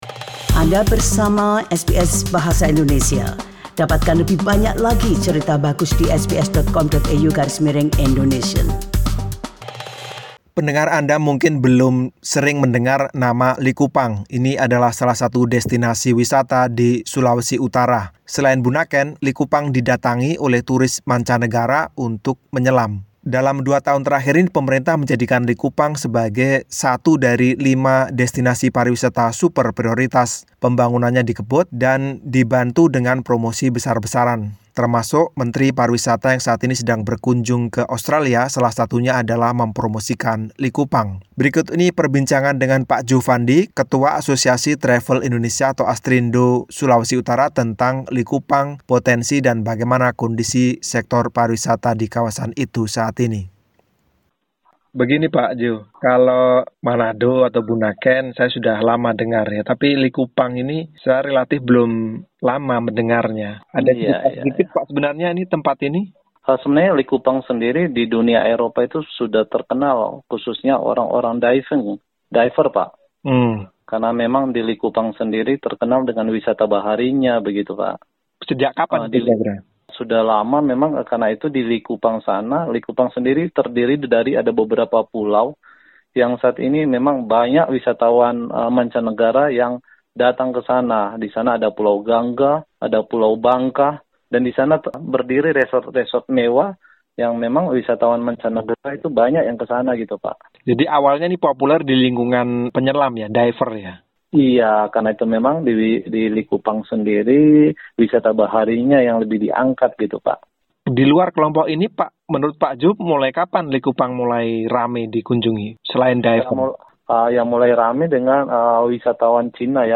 Dalam wawancara ini